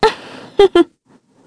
Xerah-Vox_Happy2_kr.wav